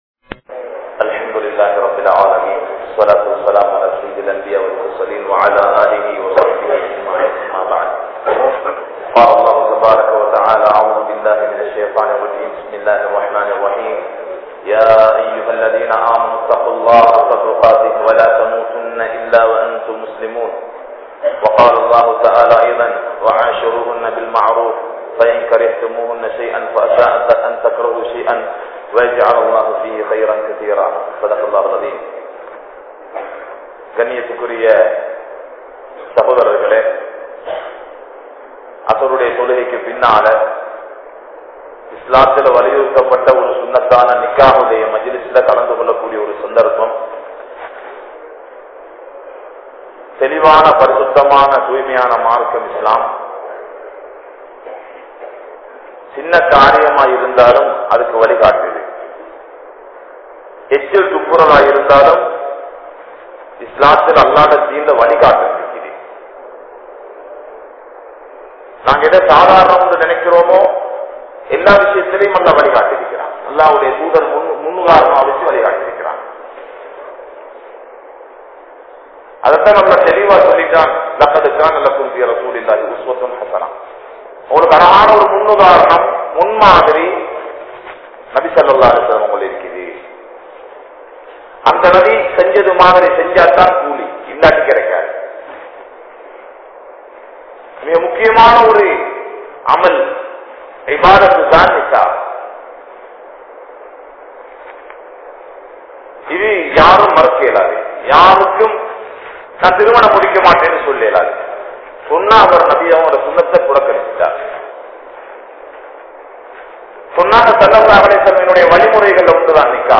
Amaanithamaana Pengal (அமானிதமான பெண்கள்) | Audio Bayans | All Ceylon Muslim Youth Community | Addalaichenai